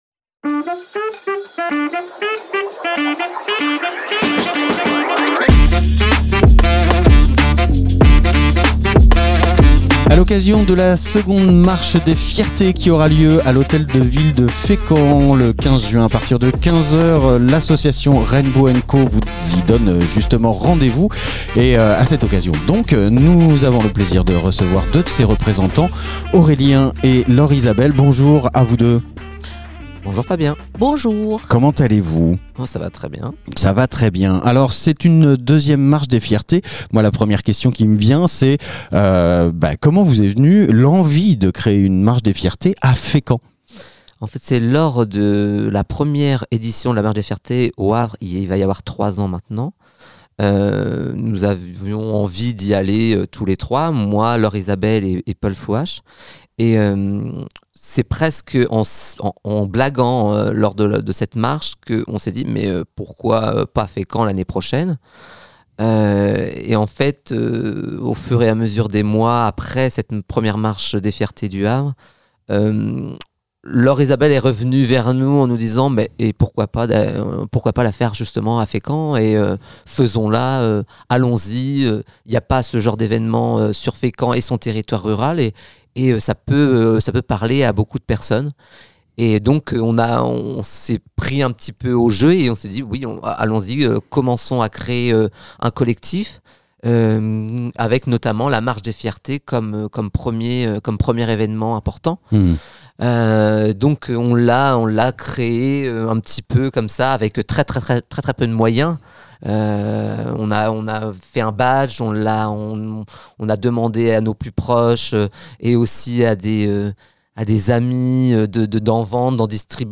Régulièrement, différentes associations Fécampoises viennent dans nos studios pour enregistrer leurs différentes annonces pour vous informer de leurs activités
Interview Rainbow'N'Caux - marche des fiertés 15 Juin 2024 du 03.06.2024